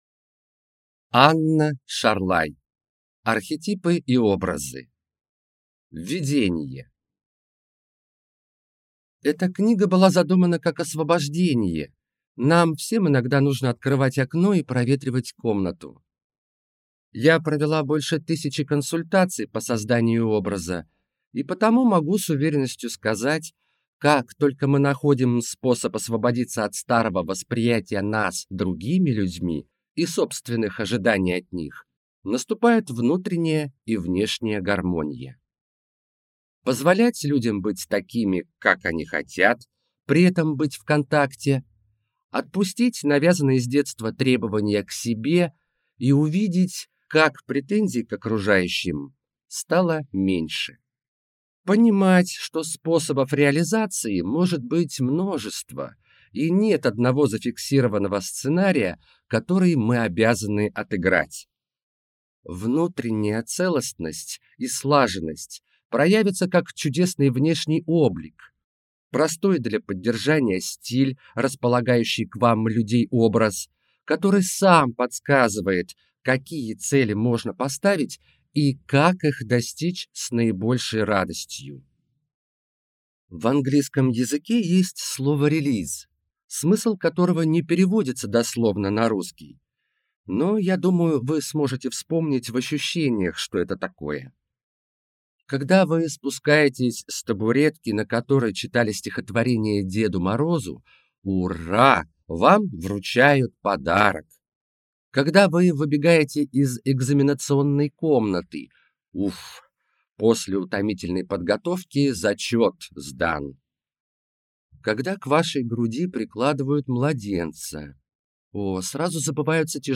Аудиокнига Архетипы и Образы | Библиотека аудиокниг